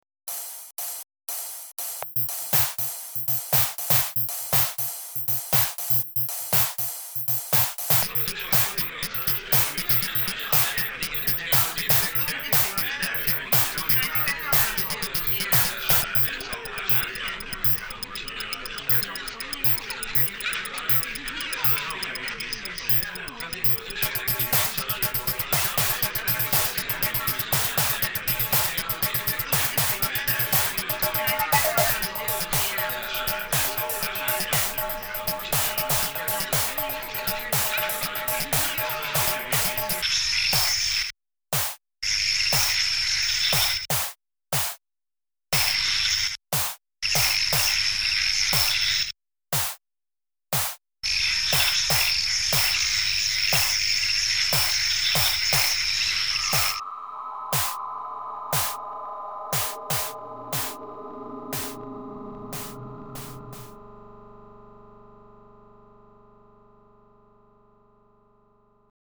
Vocals, Art Direction, Band
Synthesizer, L.S.I., Band
Keyboards, Uillean Pipes, Band